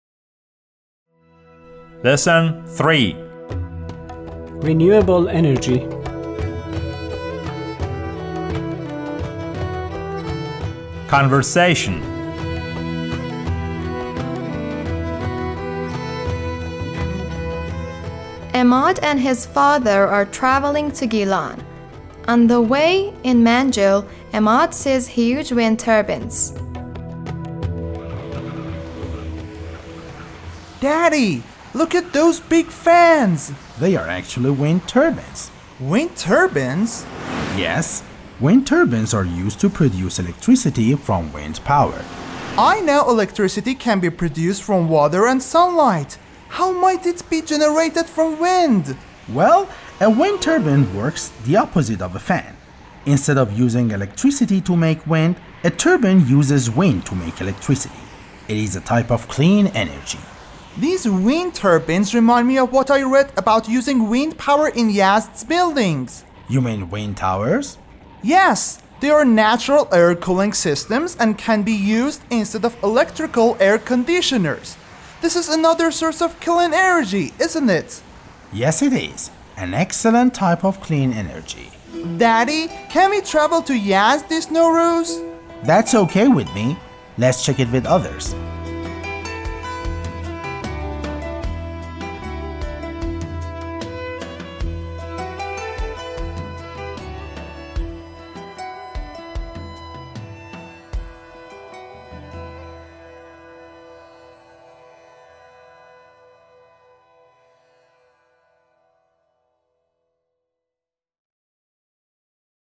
12-L3-Conversation.mp3